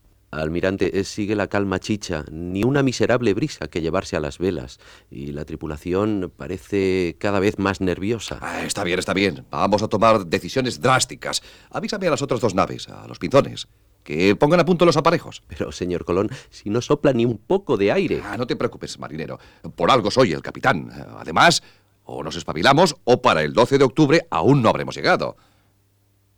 Diàleg ficcionat entre Cristobal Colón i un mariner